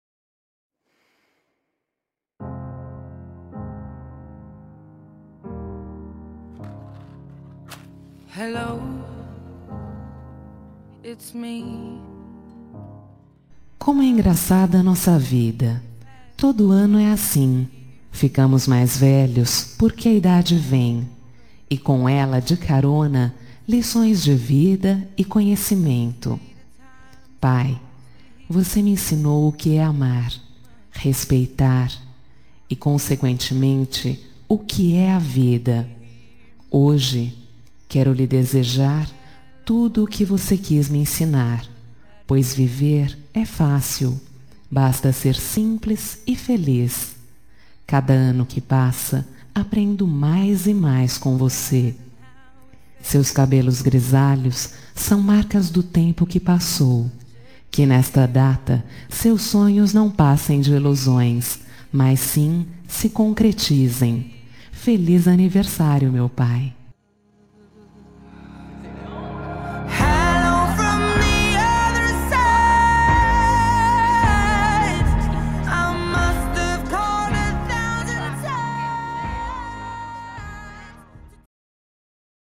Telemensagem de Aniversário de Pai – Voz Feminina – Cód: 1456